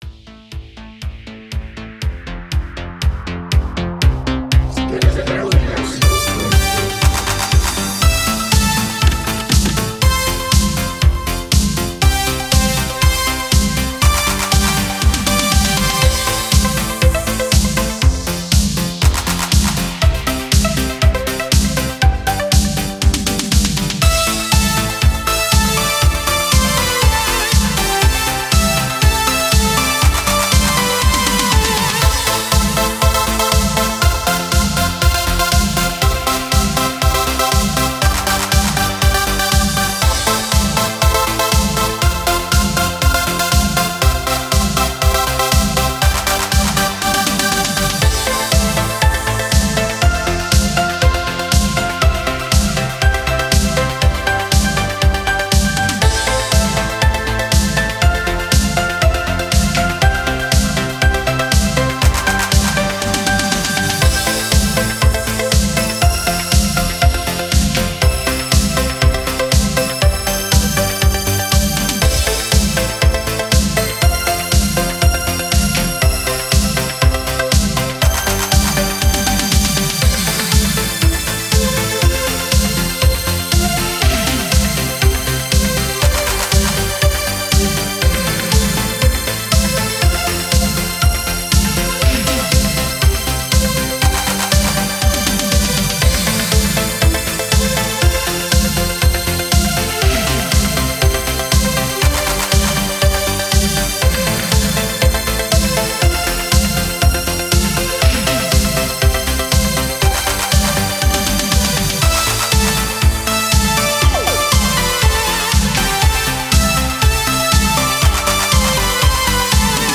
Пару инструментальчиков в этом стиле)